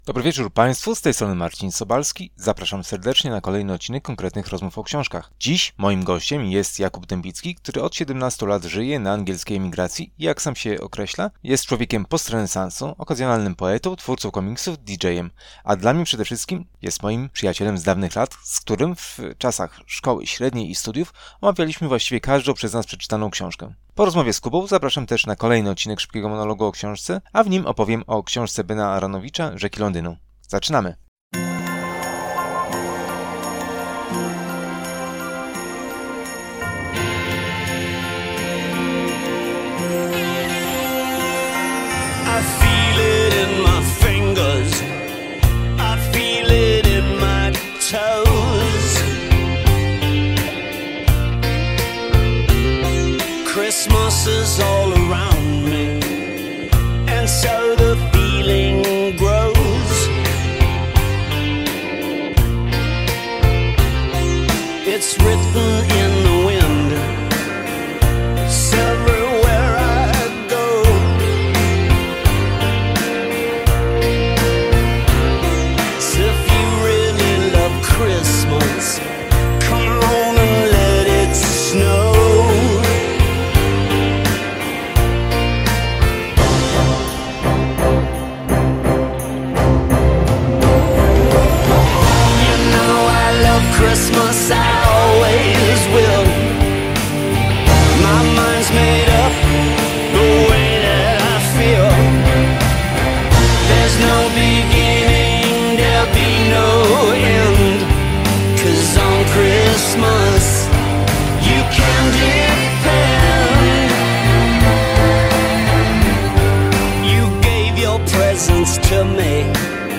A na zakończenie pojawi się Szybki Monolog o Książce.